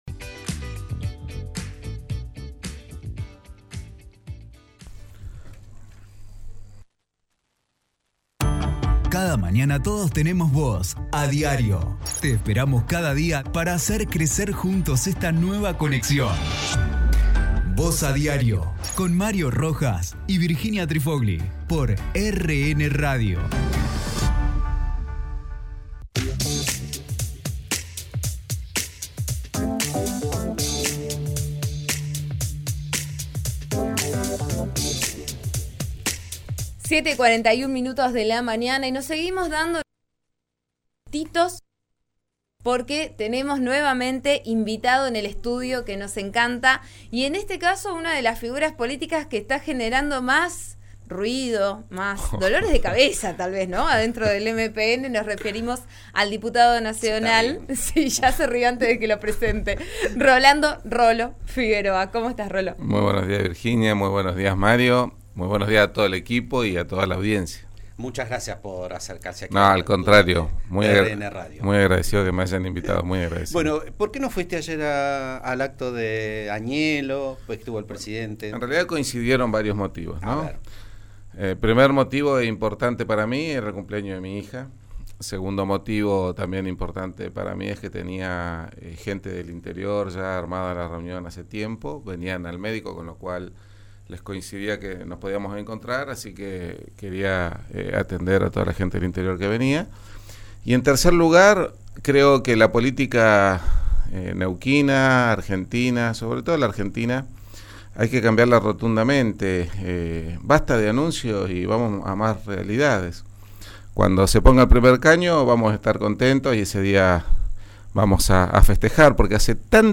En una entrevista que brindó este viernes a Vos A Diario de RN Radio 89.3, el exvicegobernador de Neuquén detalló que ayer también tenía el cumpleaños de una de sus hijas y una reunión con gente del interior que no podía postergar.